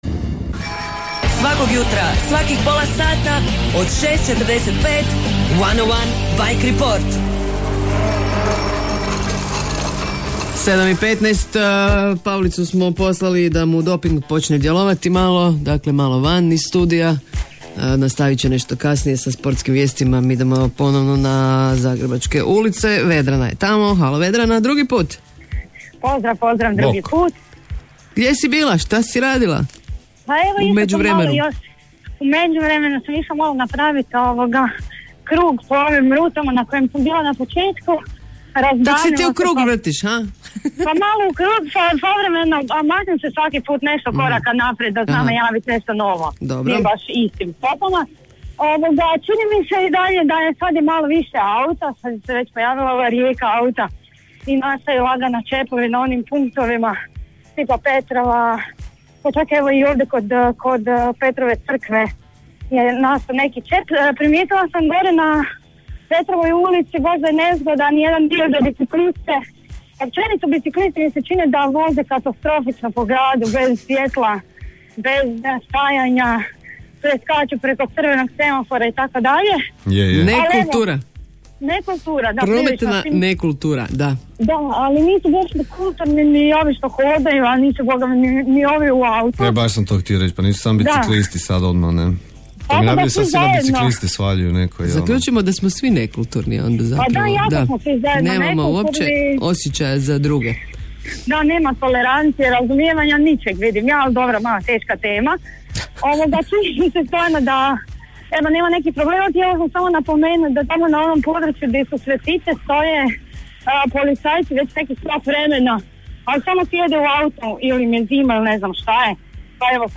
Od ponedjeljka do petka, od ranog jutra do Phone Boxa u eteru Radija 101 očekujte redovita javljanja naših dežurnih bi-reportera sa zagrebačkih cesata.